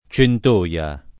Centoia [ © ent 1L a ]